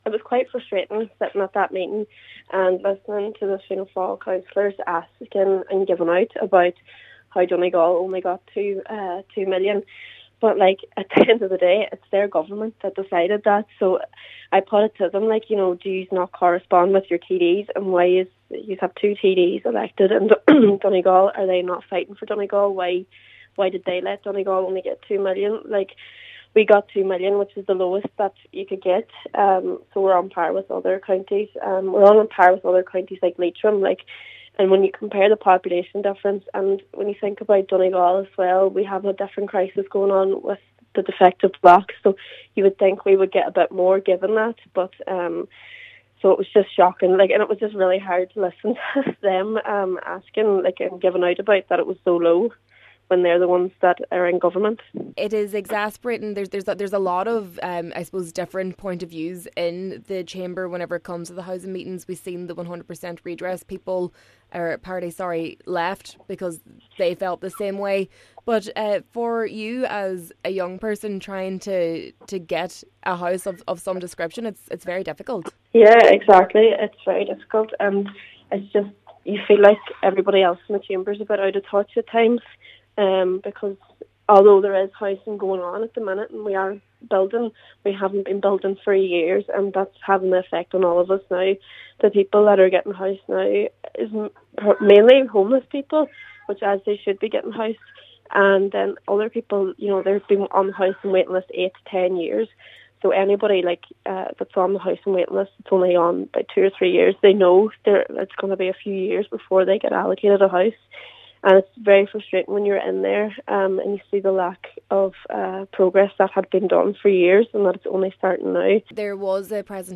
While expressing her disappointment with the scheme, Cllr Dakota Nic Mheanmeann asked her Fianna Fail colleagues in the chamber during a special housing meeting if they were asking their TDs to demand more for Donegal………..